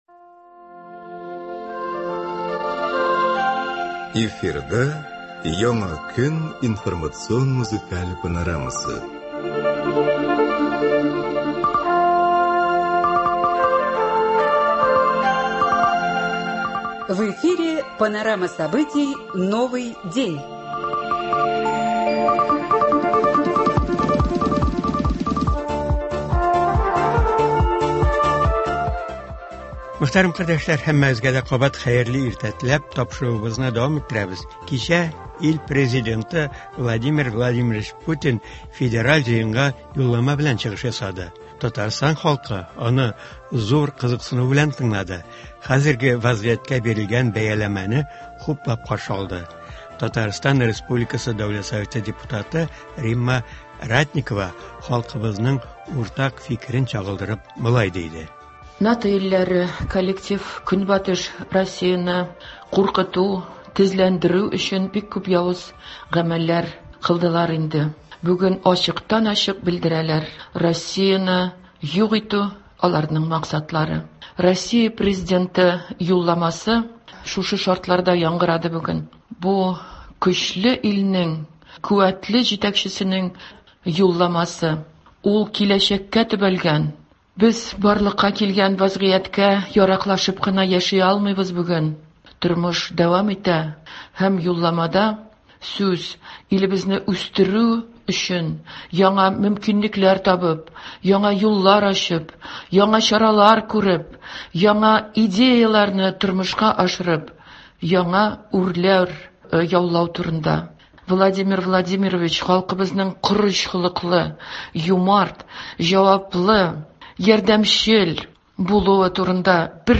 Туры эфир (22.02.23)